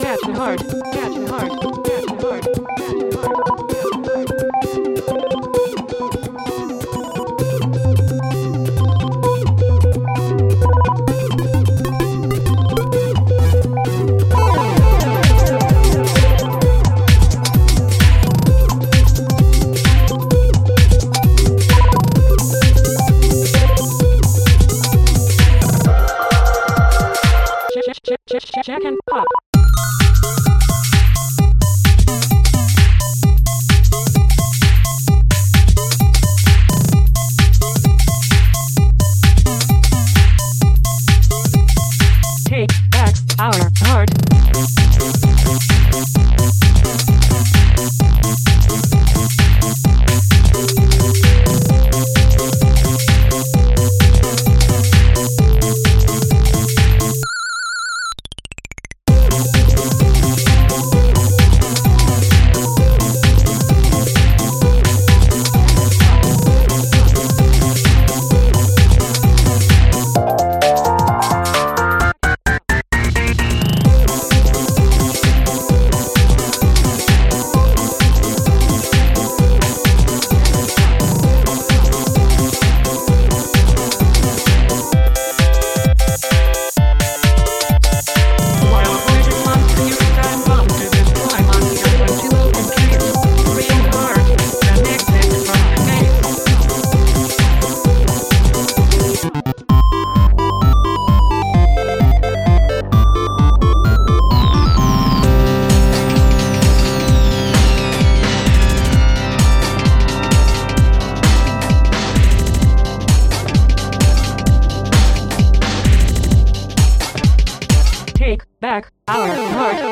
ガラージュとしてようやく完成に漕ぎ着けました
macOS Text-to-Speech Agnes
YMCK Magical 8bit Plug
Roland TR-808 & 909